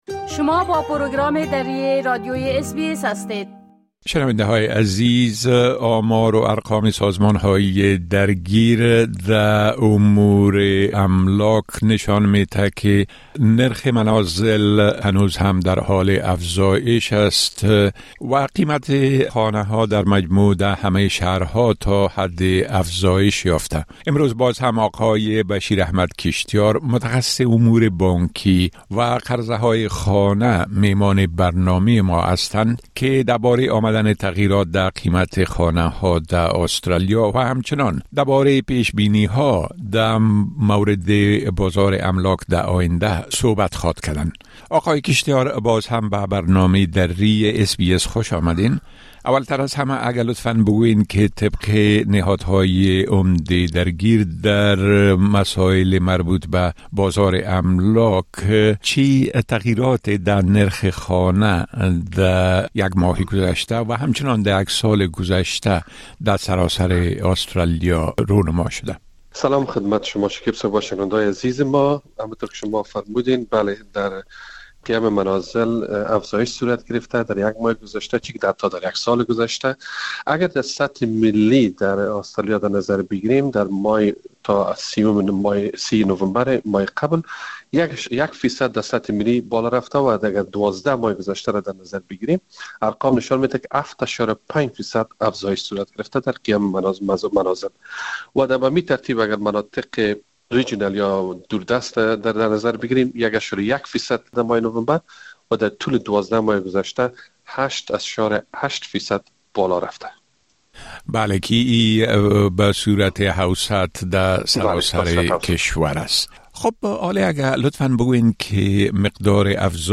از افزایش قیمت منازل تا پیش‌بینی آینده؛ گفتگو با کارشناس امور قرضه‌های خانه درباره بازار املاک